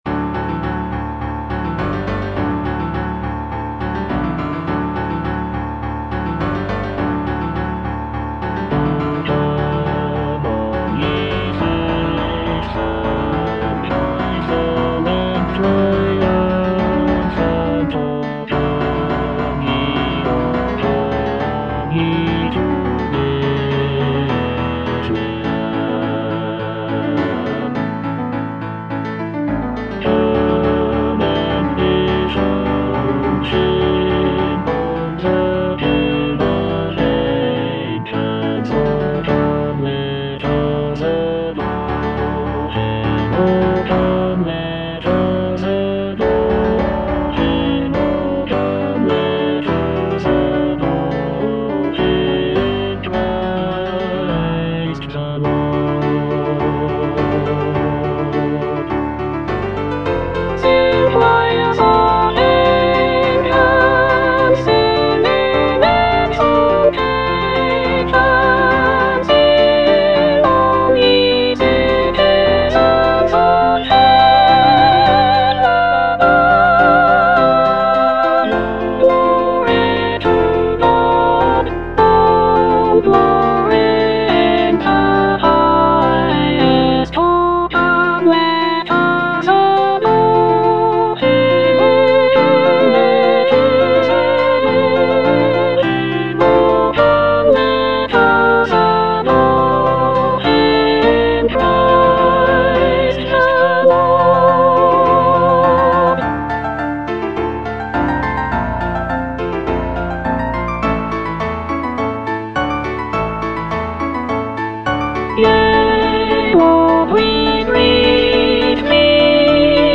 Soprano I (Emphasised voice and other voices)
incorporating lush harmonies and intricate vocal lines.